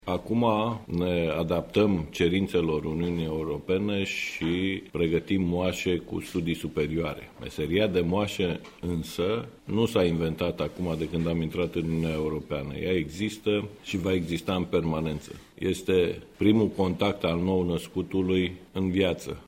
Medicul ginecolog